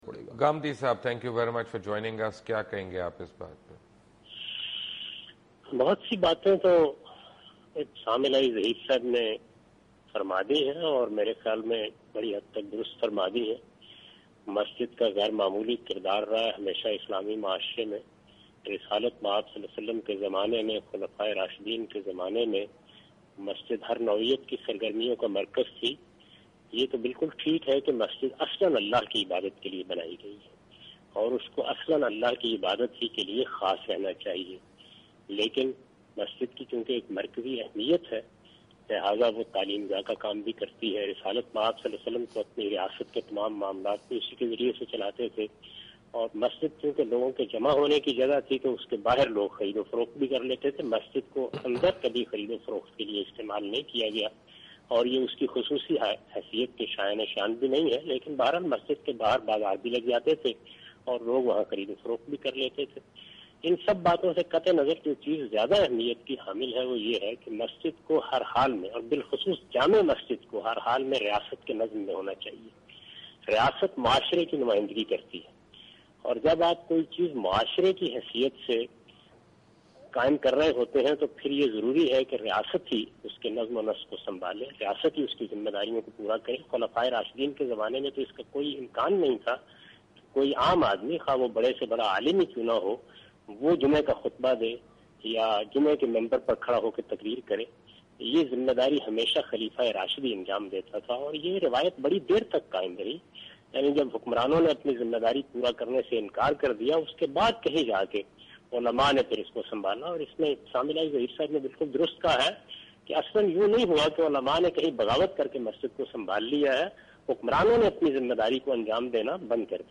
Category: TV Programs / Dunya News /